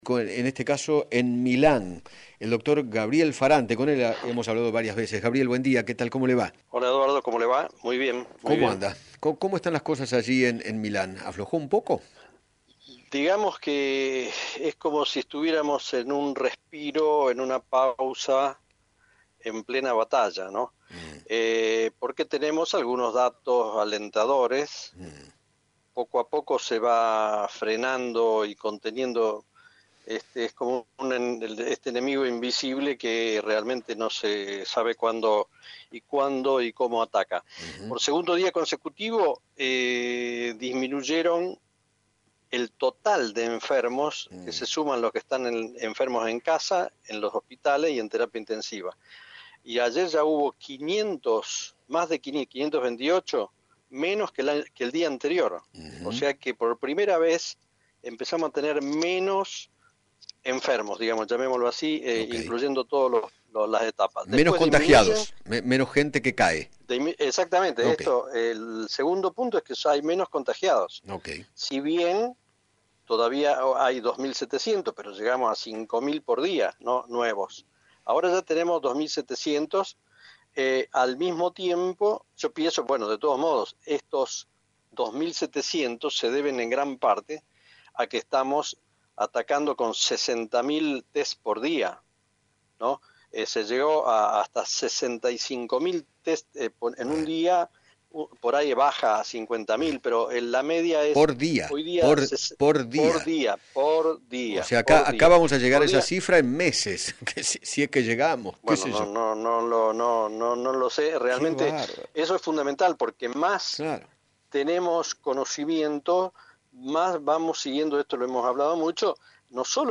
dialogó con Eduardo Feinmann sobre el descenso de la curva de contagios en Italia y sostuvo que “poco a poco se va frenando y conteniendo este enemigo invisible”.